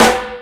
Mega Drums(06).wav